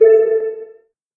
quitSound.mp3